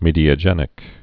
(mēdē-ə-jĕnĭk)